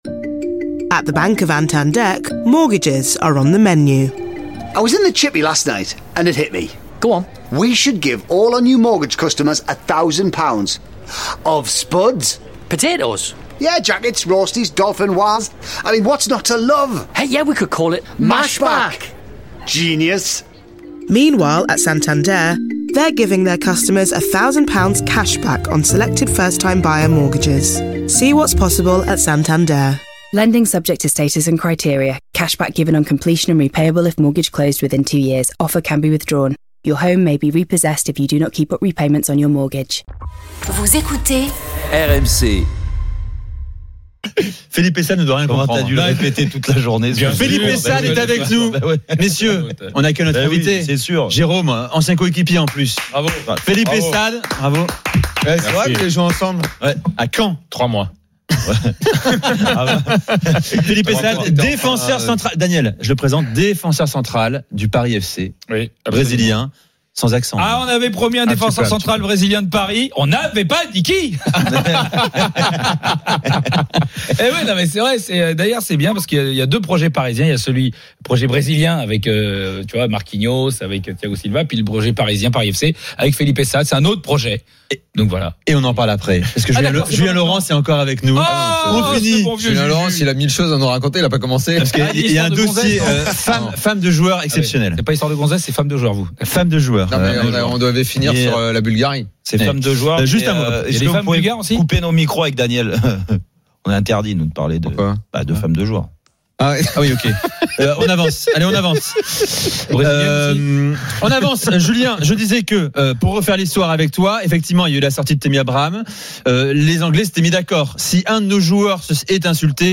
Le Top de l'Afterfoot : L'interview de Felipe Saad par Julien Cazarre – 10/10